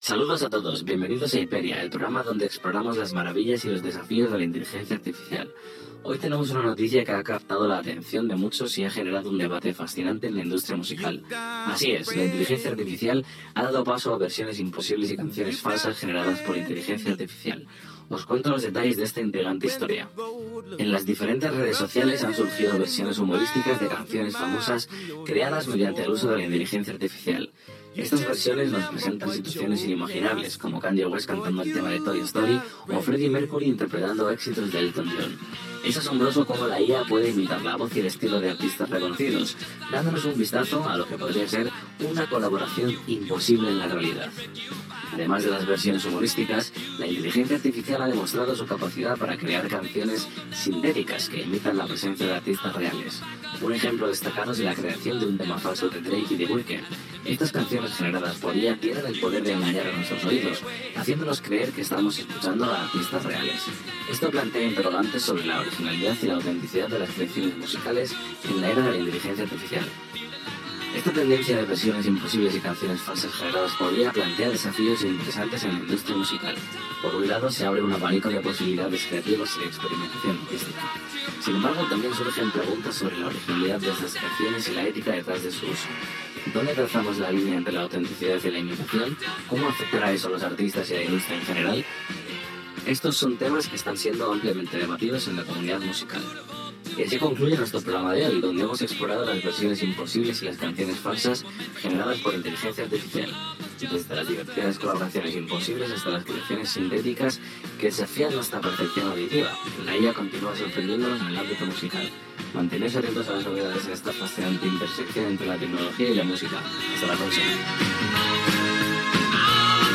Hiperia (veu sintètica en castellà)
Primer contingut audiovisual (guió, imatge i veu) creat amb sistemes d'intel·ligència artificial i veu sintètica, programats per persones.